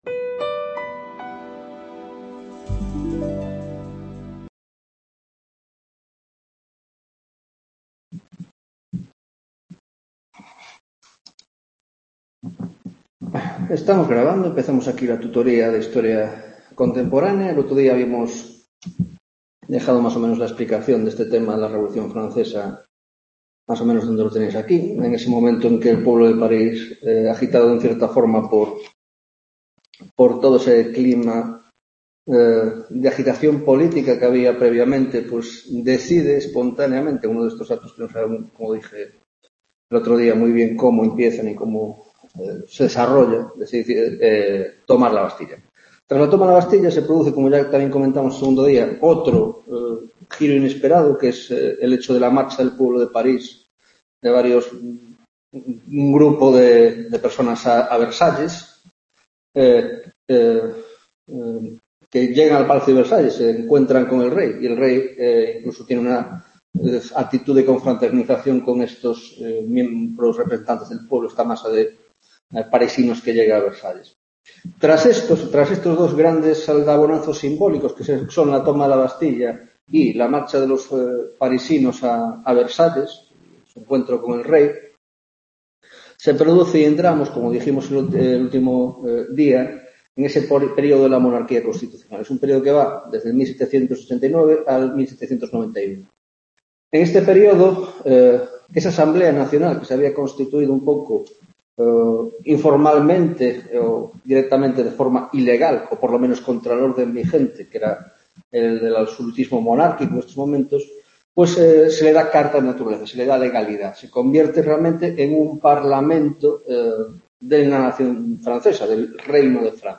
4ª Tutoría de Historia Contemporánea - Revolución Francesa, 2ª parte